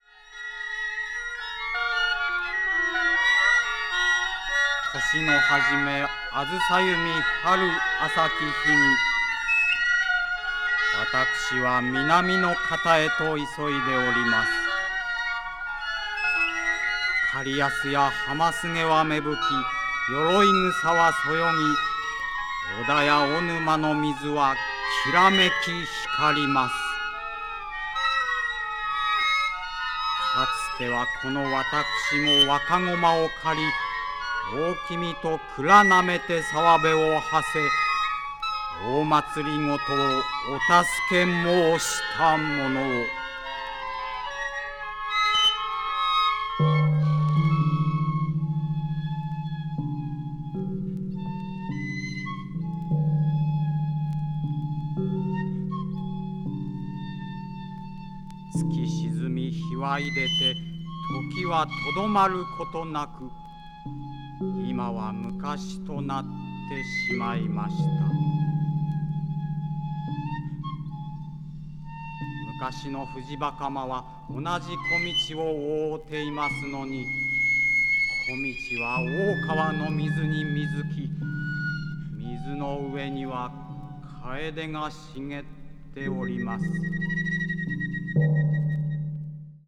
media : EX+/EX(わずかにチリノイズが入る箇所あり)